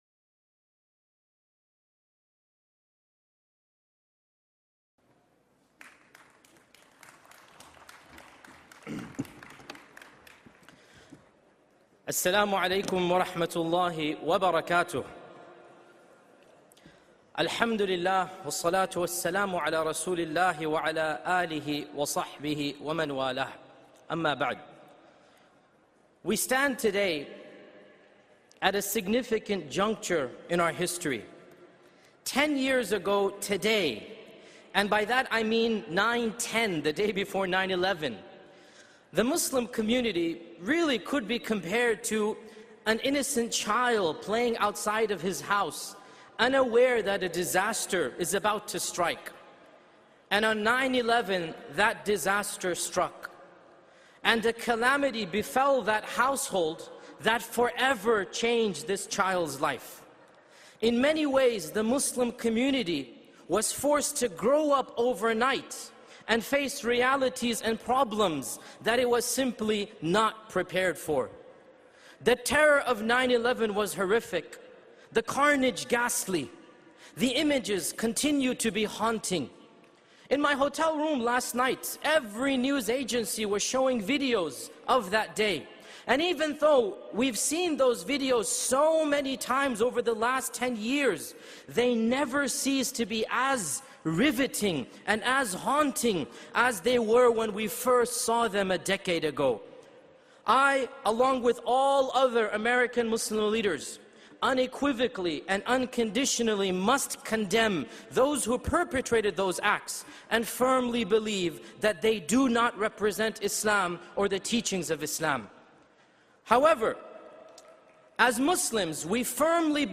On the tenth anniversary of September 11, 2001, one of America’s most prominent Muslim scholars delivered a landmark address reflecting on what that devastating day meant for the Muslim community and where it must go from here. With characteristic candor, he unequivocally condemns the perpetrators of the attacks while challenging Muslims to transform their grief and hardship into lasting, proactive change rather than merely reacting to the next crisis.